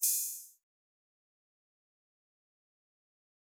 openhat tmog luger metro lab.wav